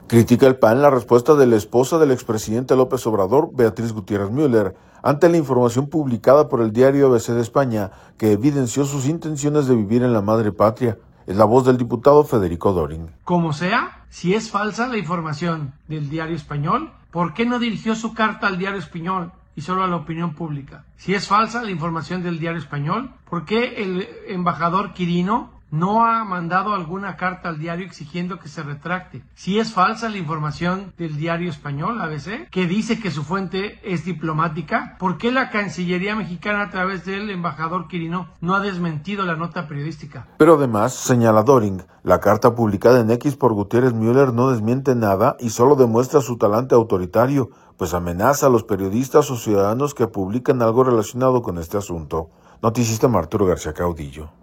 audio Critica el PAN la respuesta de la esposa del ex presidente López Obrador, Beatriz Gutiérrez Müller, ante la información publicada por el Diario ABC de España, que evidenció sus intenciones de vivir en la Madre Patria. Es la voz del diputado Federico Döring.